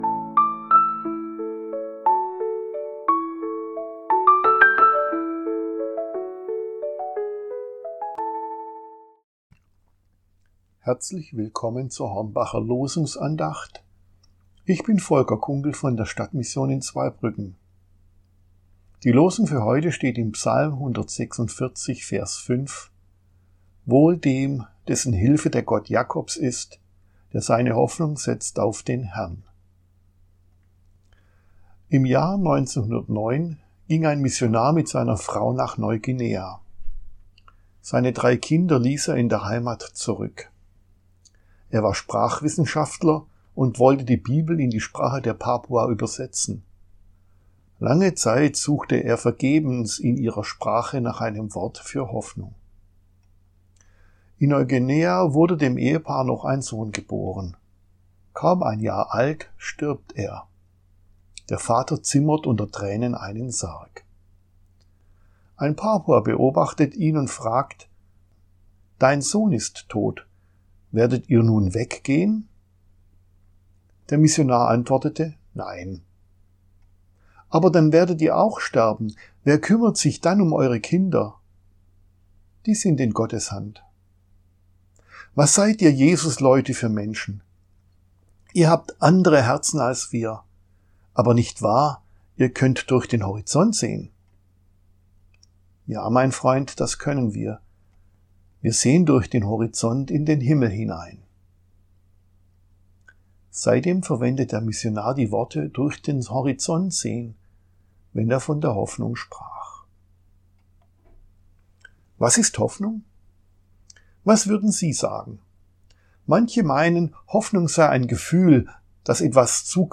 Losungsandacht für Freitag, 03.04.2026 – Prot.